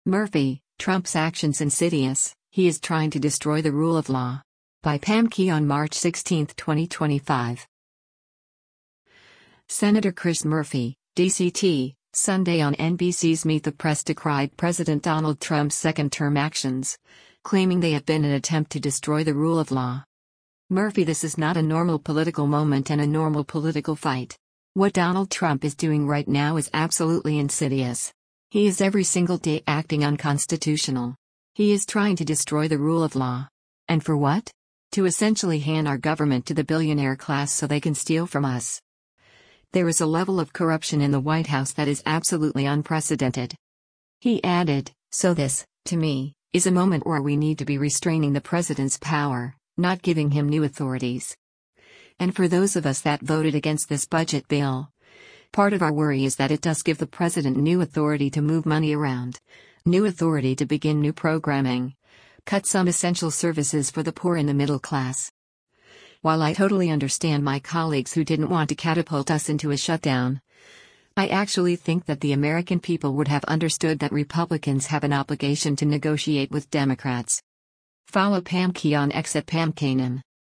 Senator Chris Murphy (D-CT) Sunday on NBC’s “Meet the Press” decried President Donald Trump’s second term actions, claiming they have been an attempt to destroy the rule of law.